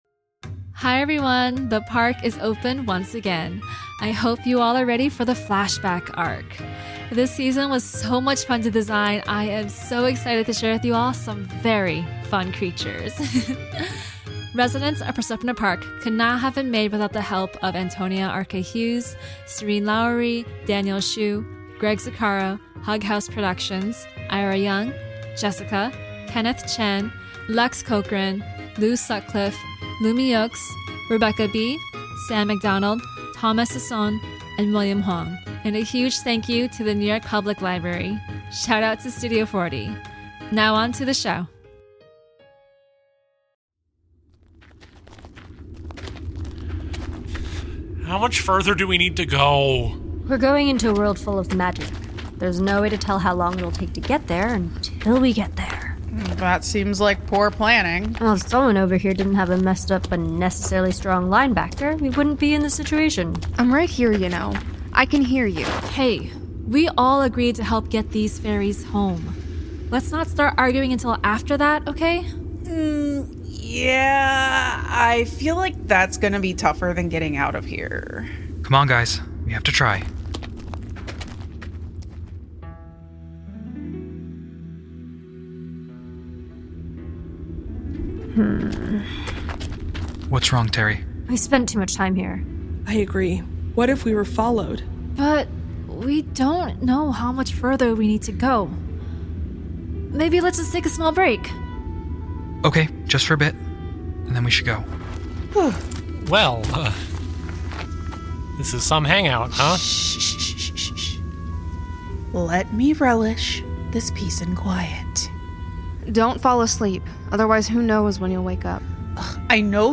Listen to fantastic tales and origins of beasts, monsters, and gods, told in an immersive, full-cast, anime-inspired audio drama.